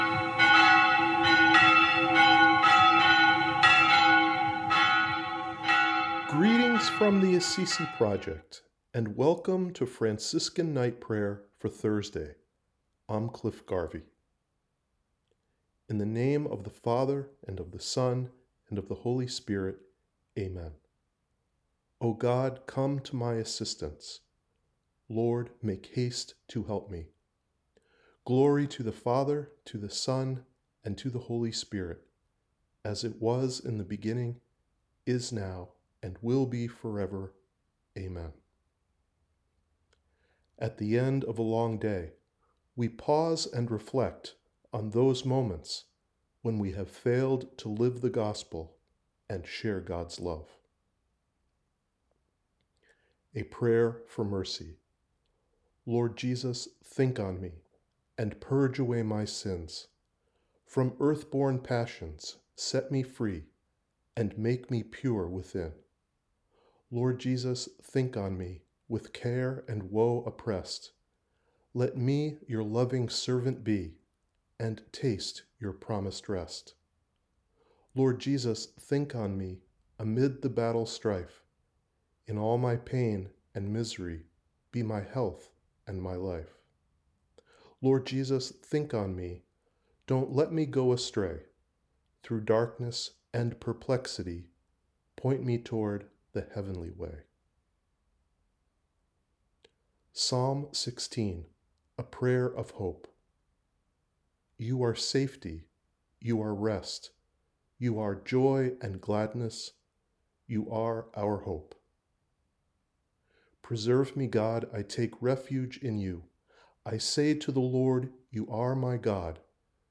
ap-thu-night-prayer.wav